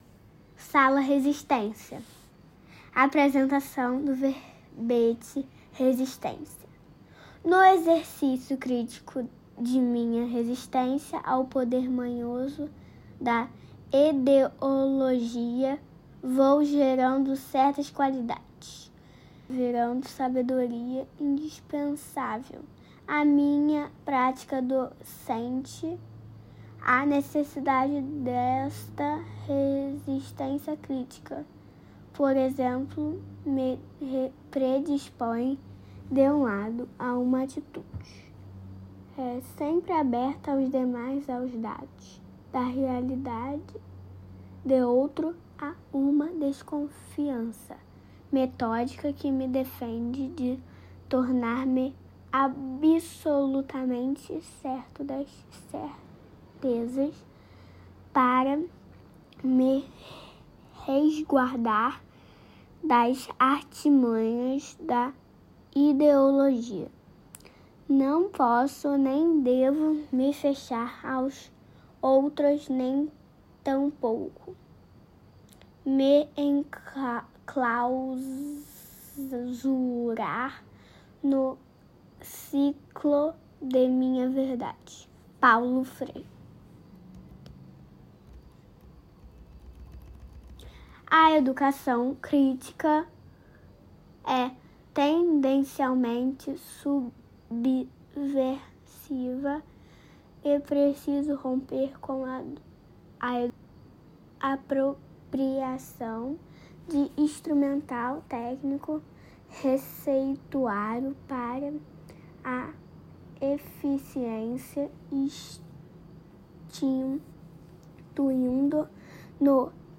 Verbete com voz humana